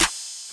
YJSRSnare 2.wav